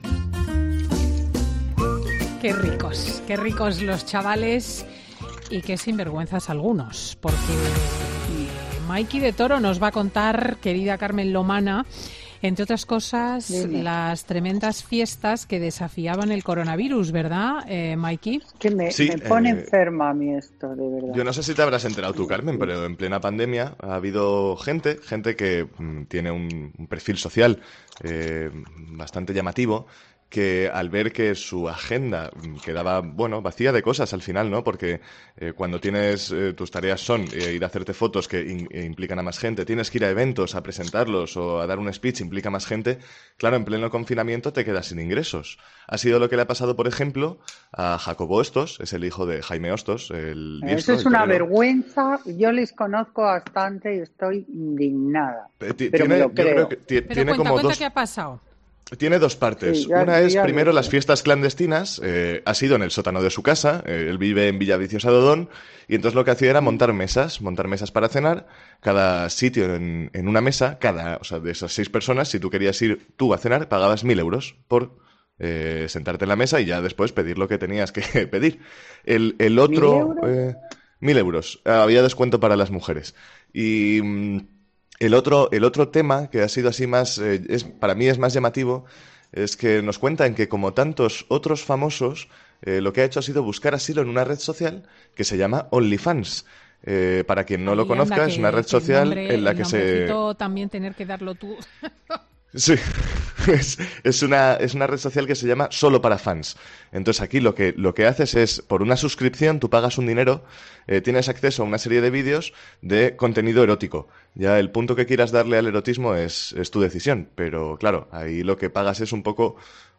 A la socialité no le ha gustado nada la exclusiva del ‘Programa de Ana Rosa’ y ha estallado en directo: “Me pone en enferma”.